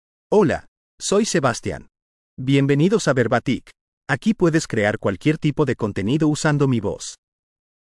MaleSpanish (United States)
SebastianMale Spanish AI voice
Sebastian is a male AI voice for Spanish (United States).
Voice sample
Listen to Sebastian's male Spanish voice.
Sebastian delivers clear pronunciation with authentic United States Spanish intonation, making your content sound professionally produced.